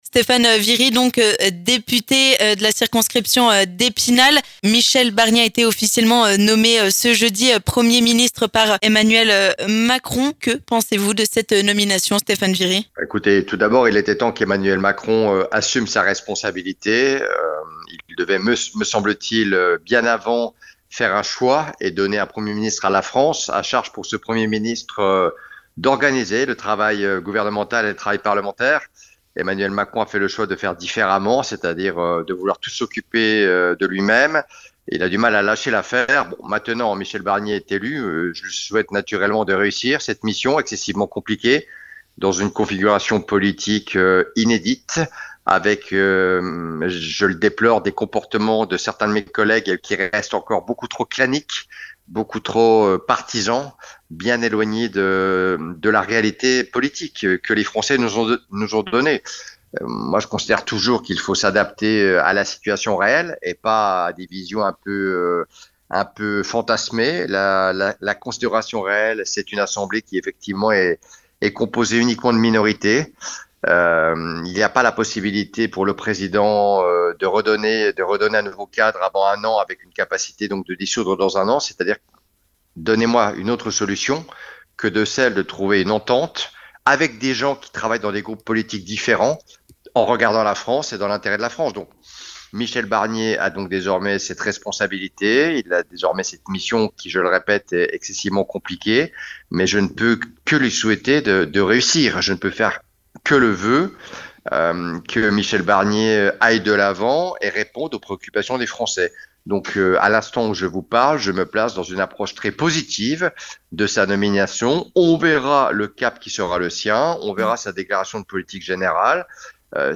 Stéphane Viry, député de la première circonscription des Vosges, réagit à cette nomination.